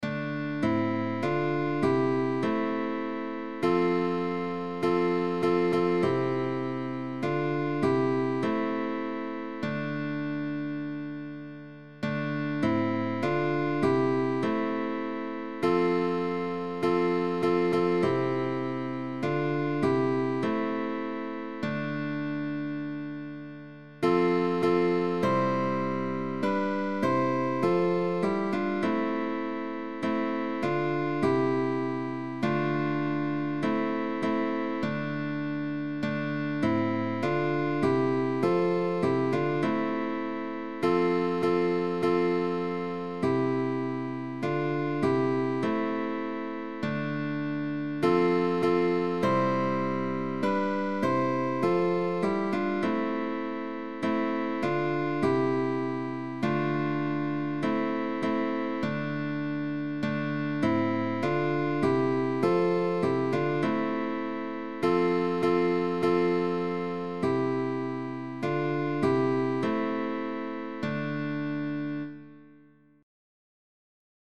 TRÍO DE GUITARRAS Índice y medio: Pulsación "Apoyando".
Índice y medio: Pulsación «Apoyando».
Pulgar: Melodía en cuerdas graves.
Etiqueta: Musica antigua